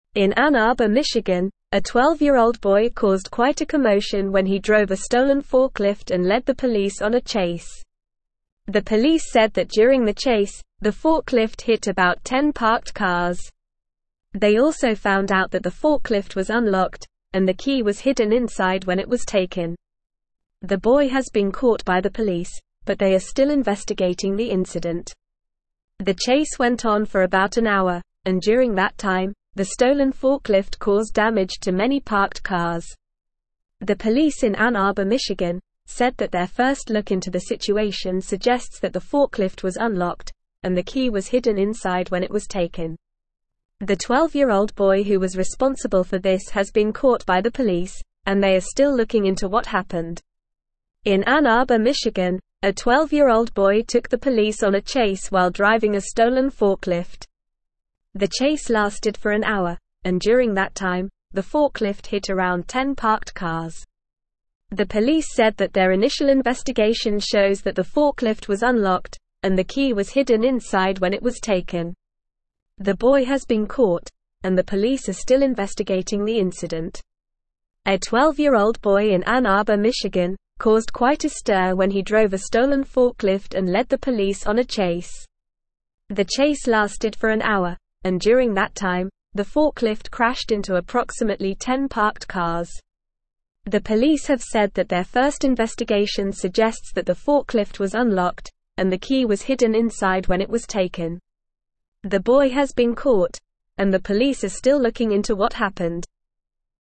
English-Newsroom-Upper-Intermediate-NORMAL-Reading-12-Year-Old-Boy-Steals-Forklift-Leads-Police-on-Chase.mp3